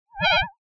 It uses an FM Synth that I wrote (Phase Modulation, more accurately) that has a carrier/modulator pair, an LFO (for either pitch or volume uses) and some frequency sweep options and an ASR envelope. With this, there are actually some tricky and odd sounds that can be made besides clicks.